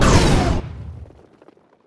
youhit3.wav